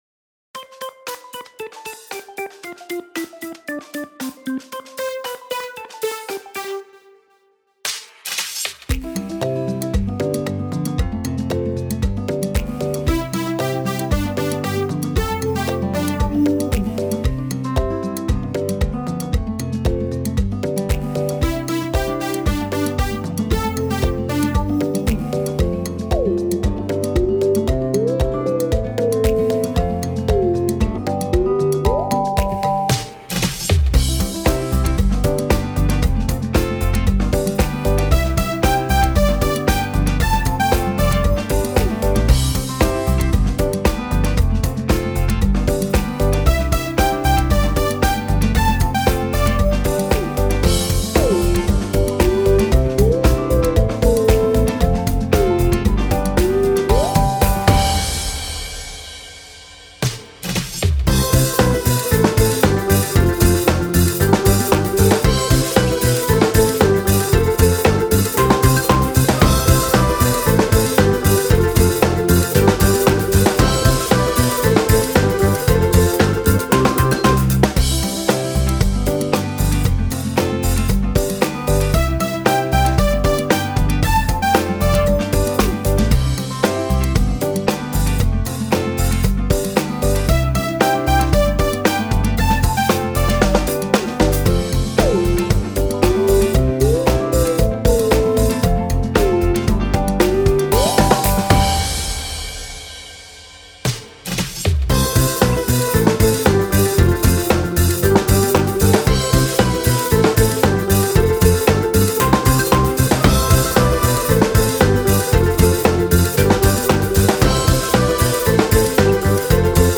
Karaoke versija